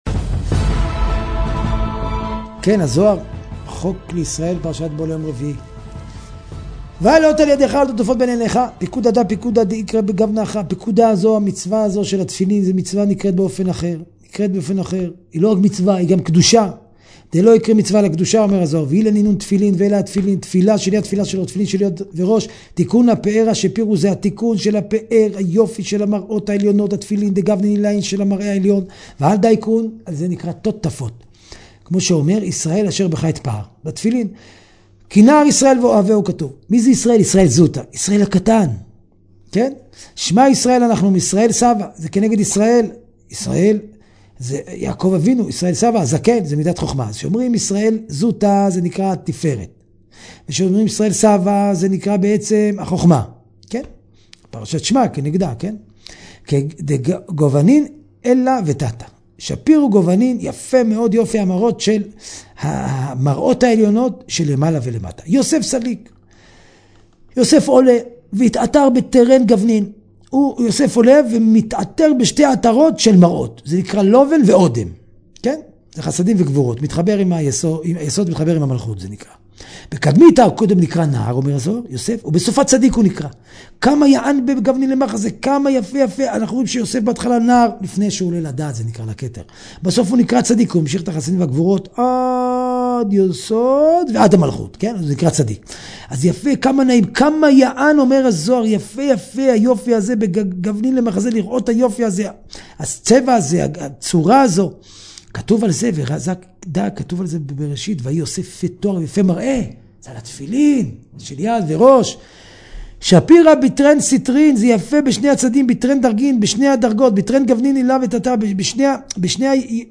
שיעורי תורה